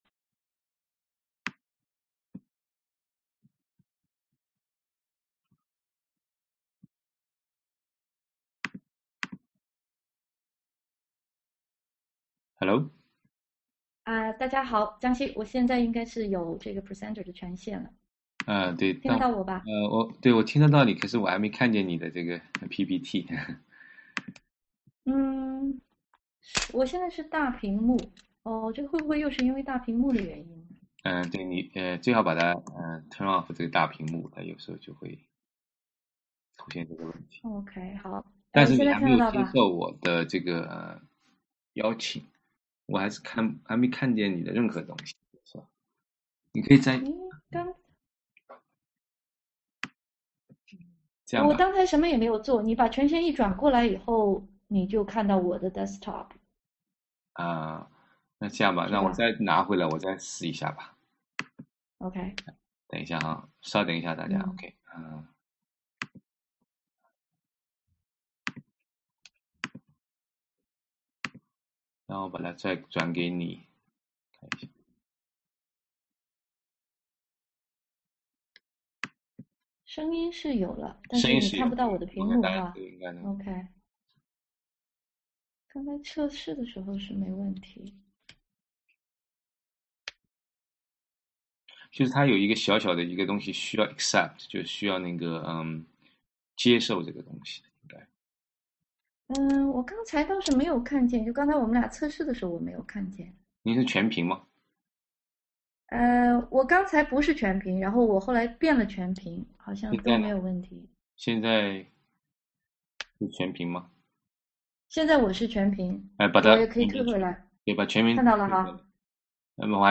另外，这里有一个几年前讲的有关这个考试的一个讲座音频，可以学习一下。